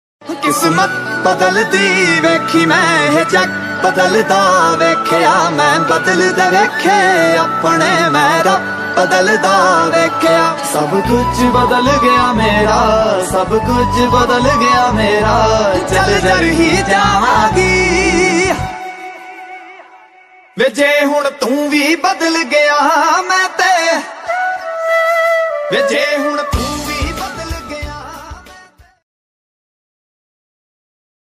Punjabi Ringtone